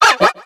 Cri de Couaneton dans Pokémon X et Y.